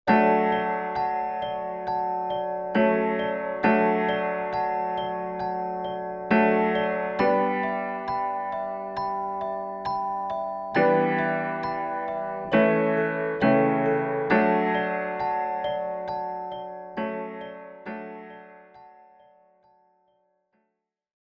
Note: In these examples, Version A is with no effect, Version B is with a gentle boost at 100Hz and 5kHz, and Version C is with a more pronounced boost at 100Hz and 10kHz.
Keys-No-Pultec.mp3